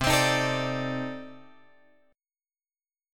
C Minor 6th Add 9th